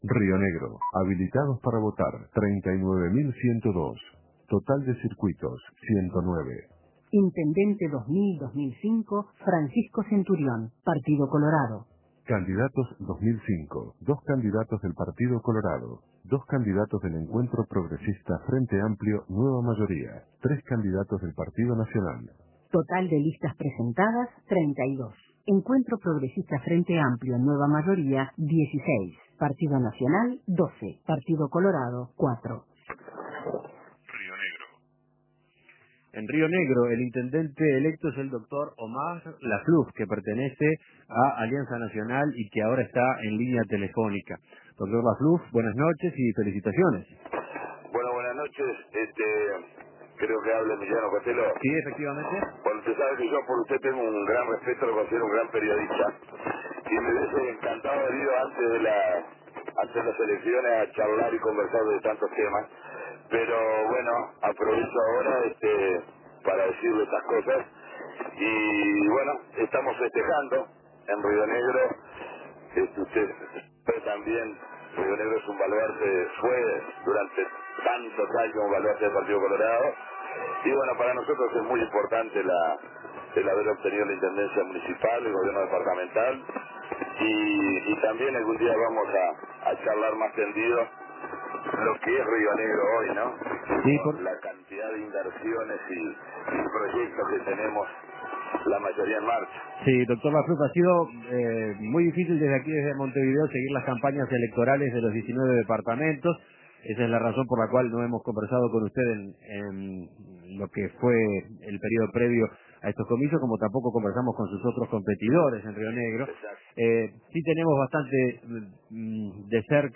Entrevistas El Partido Nacional le quitó un bastión al Partido Colorado Imprimir A- A A+ Una de las 10 intendencias del Partido Nacional es Río Negro y el nuevo intendente es Omar Lafluf, de Alianza Nacional.